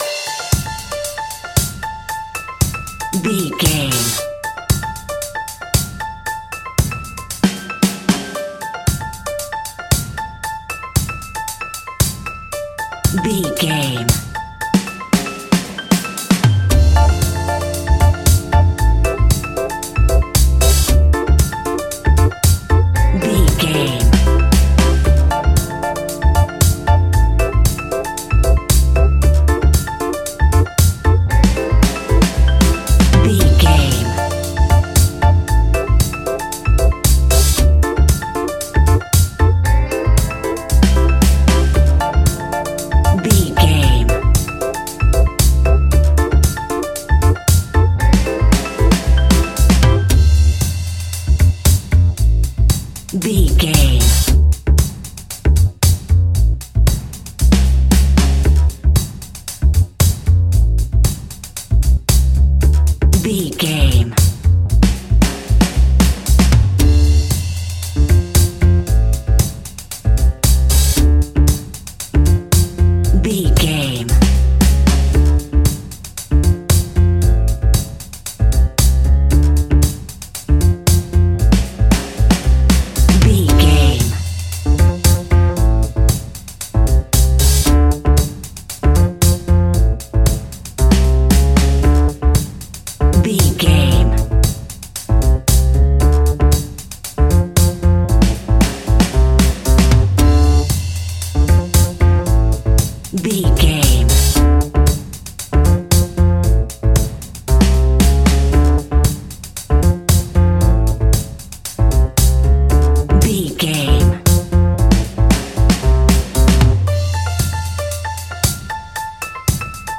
Aeolian/Minor
dub
laid back
chilled
off beat
drums
skank guitar
hammond organ
transistor guitar
percussion
horns